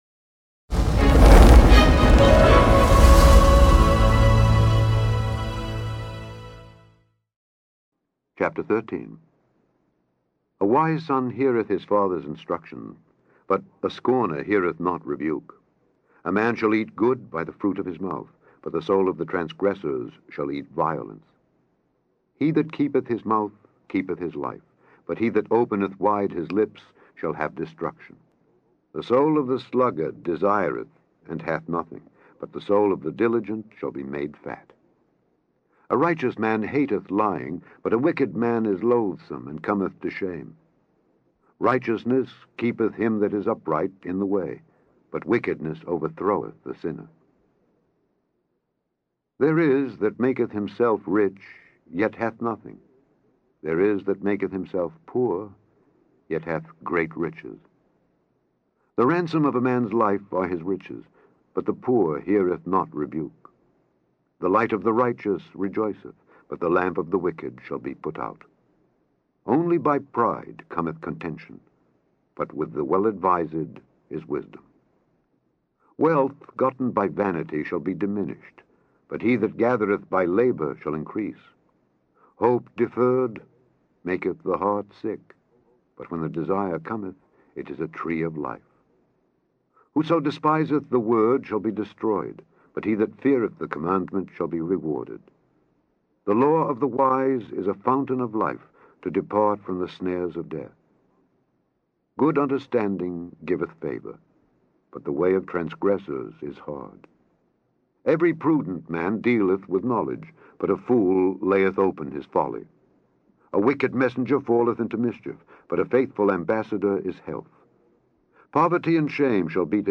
Click on the podcast to hear Alexander Scourby read Proverbs 13.